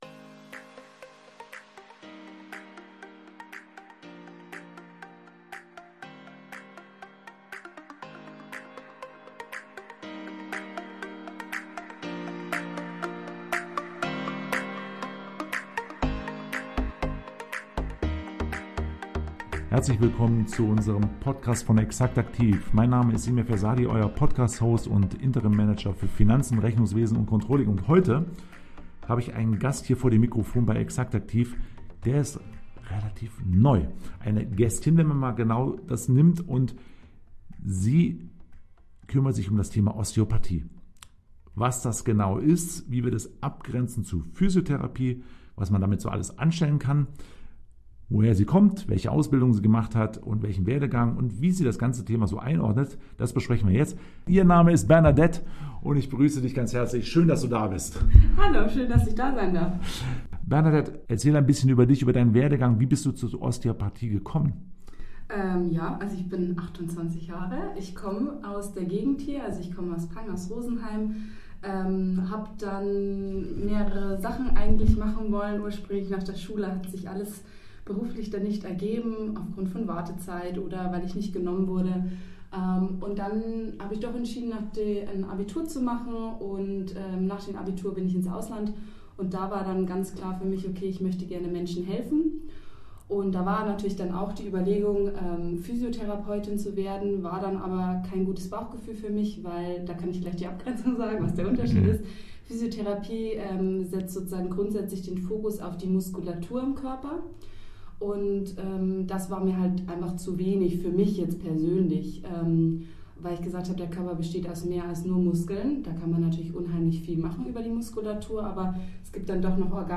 Seit 25 Jahren gibt es das exaktaktiv bereits. Das sagen langjährige Kunden und Mitarbeiter.